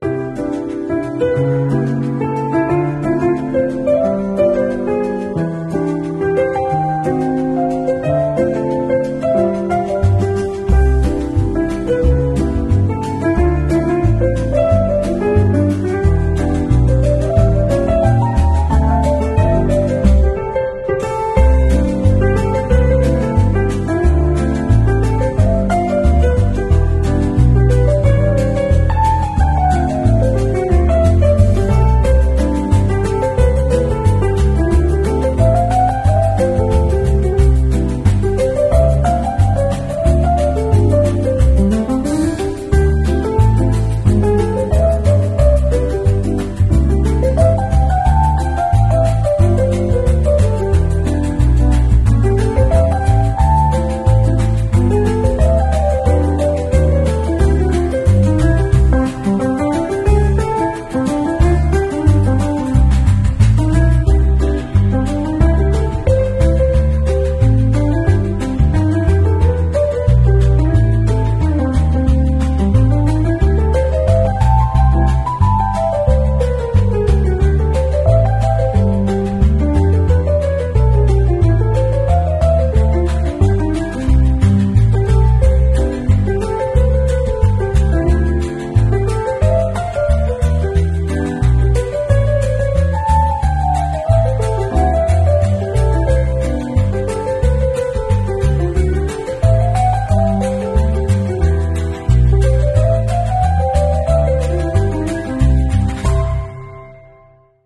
Cozy Jazz Fusion BGM